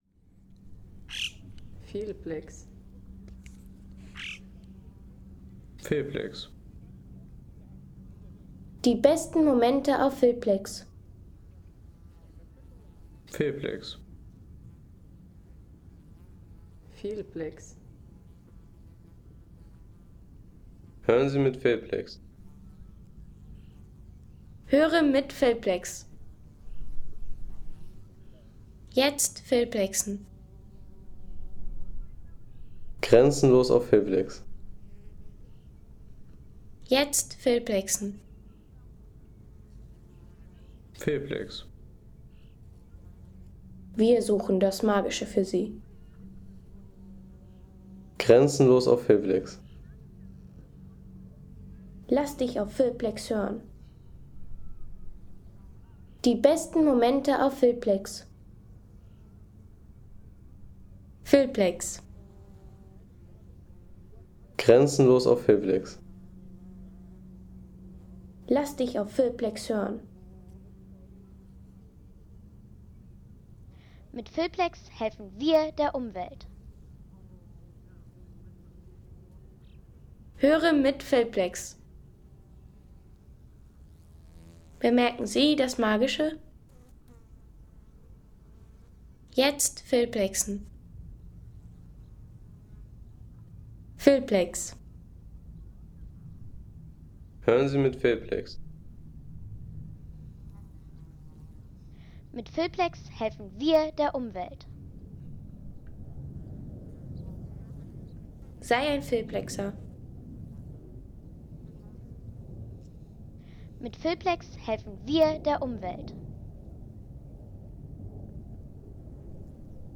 Seefelder Joch Home Sounds Landschaft Landschaft Seefelder Joch Seien Sie der Erste, der dieses Produkt bewertet Artikelnummer: 222 Kategorien: Landschaft - Landschaft Seefelder Joch Lade Sound.... Idyllisches Bergpanorama – Der Klang der Karwendeltäler in der Olym ... 3,50 € Inkl. 19% MwSt.